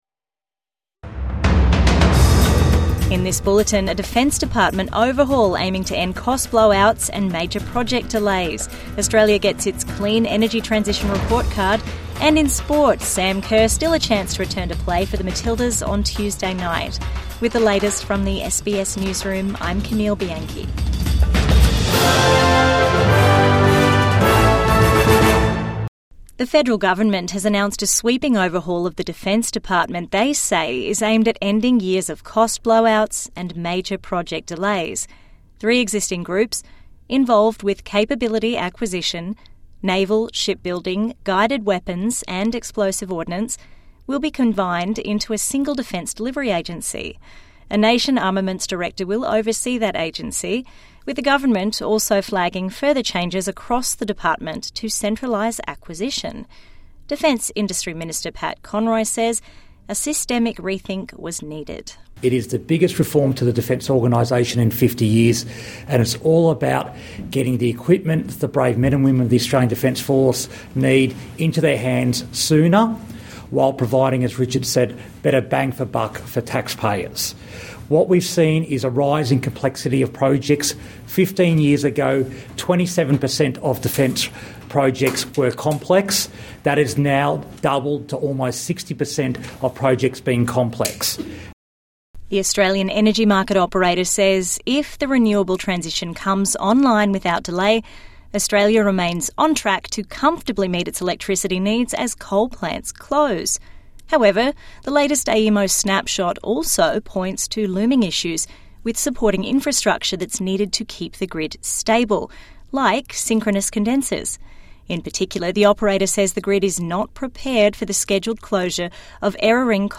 First major changes to Defence industry in 40 years | Evening News Bulletin 1 December 2025